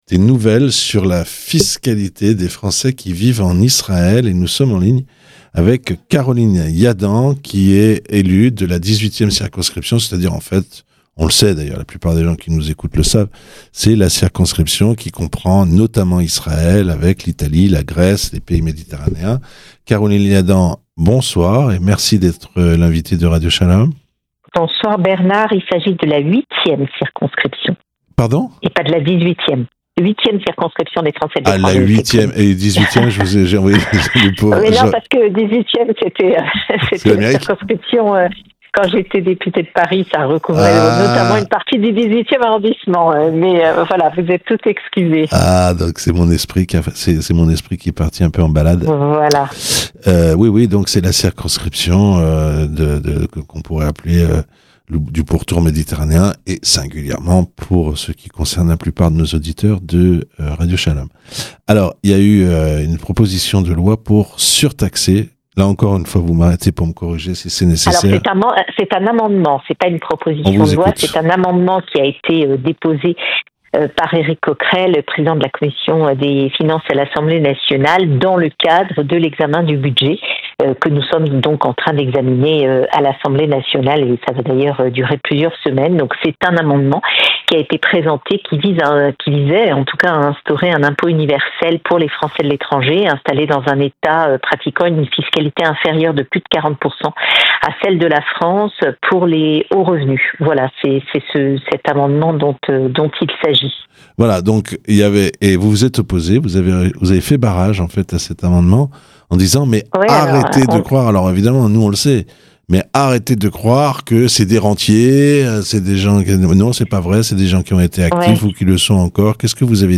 Dans un entretien à Radio Shalom, elle souligne, par ailleurs, qu'une réforme éxigeant des retraités qu'ils vivent en France , au moins six mois dans l'année, pour toucher leur pension n'est pas à l'ordre du jour.